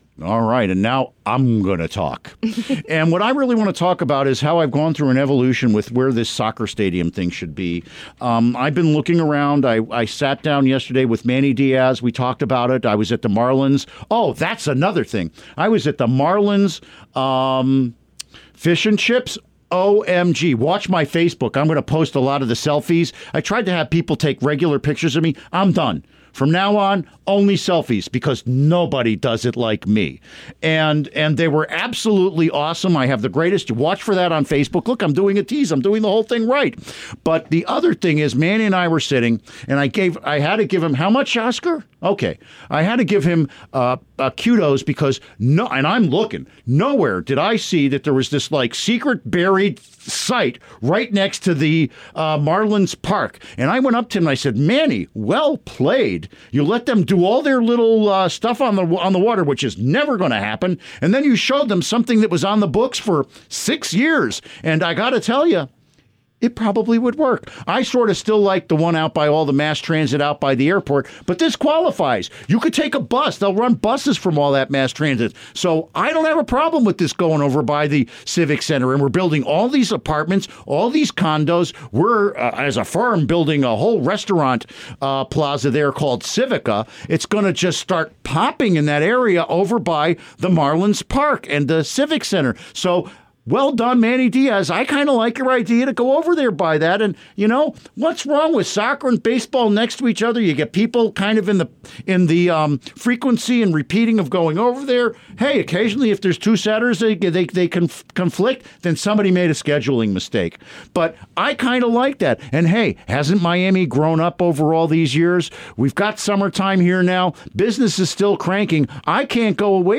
Interview Segment